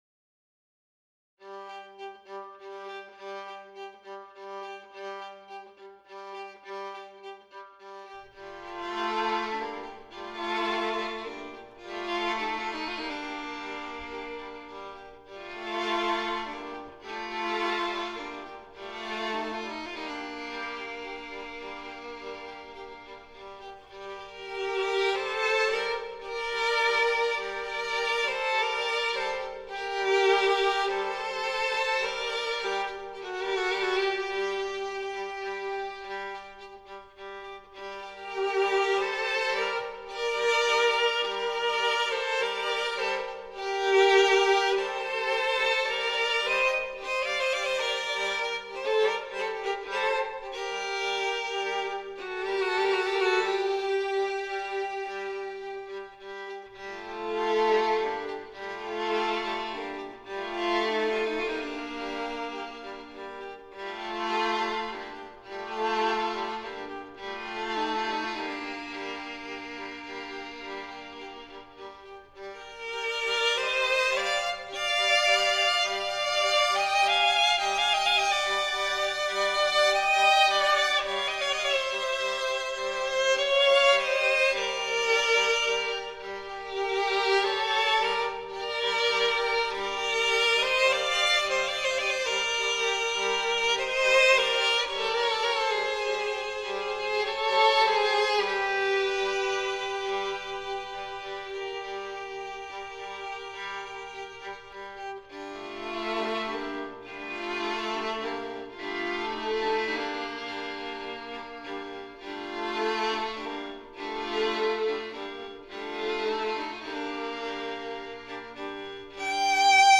An arrangement for 3 violins
Instrumentation: 3 Violins